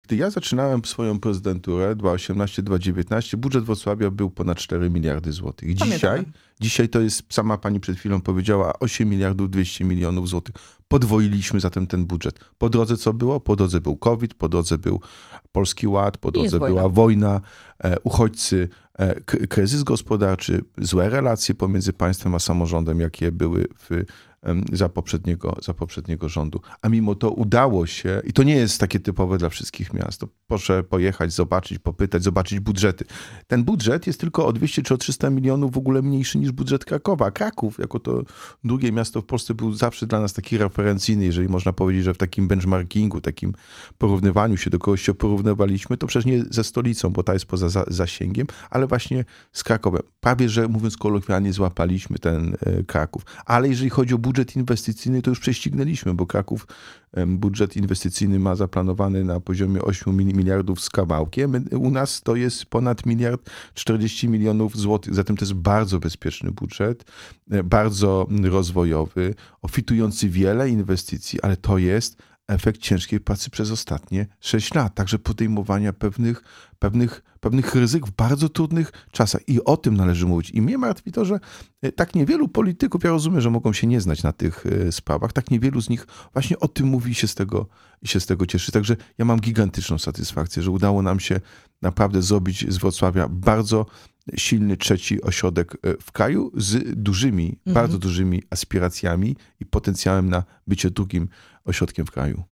Projekt budżetu na 2025 r., audyt w piłkarskim Śląsku Wrocław, sprawa zarzutów ws. Collegium Humanum – to główne tematy w naszej rozmowie z prezydentem Wrocławia.